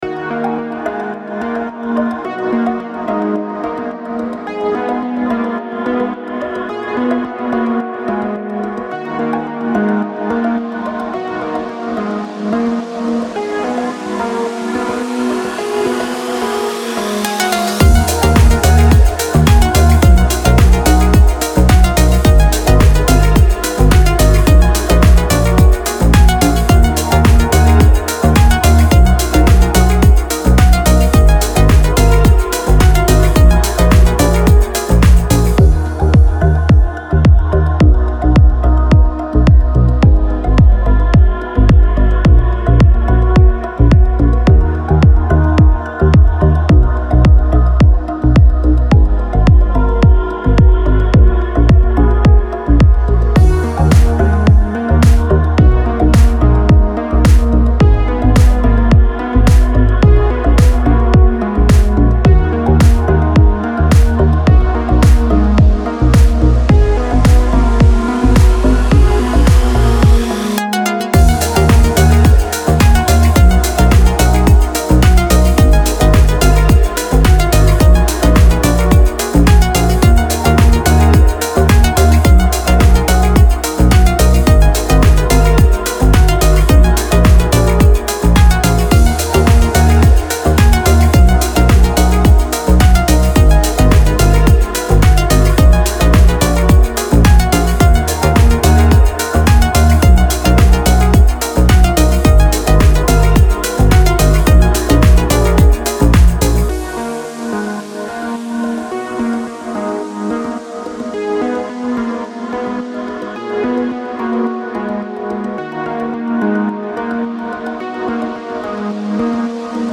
весёлая музыка
pop , dance